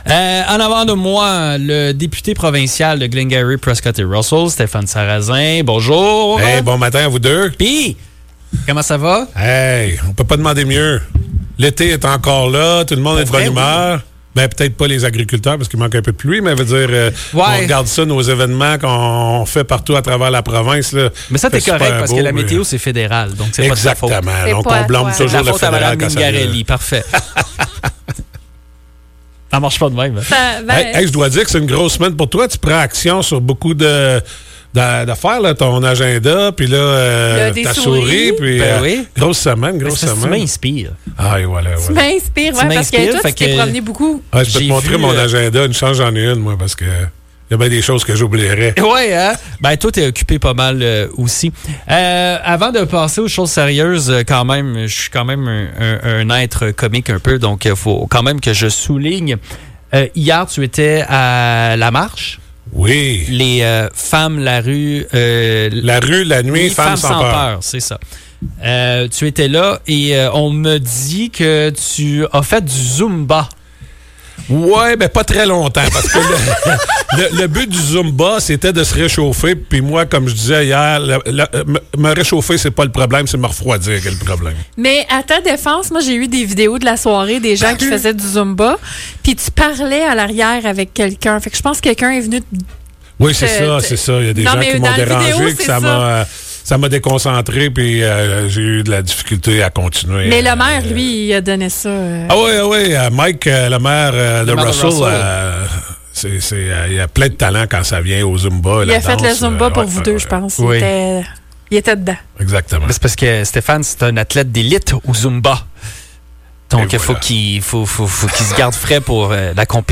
Le député provincial de Glengarry–Prescott–Russell, Stéphane Sarrazin, est venu passer une heure avec nous en studio.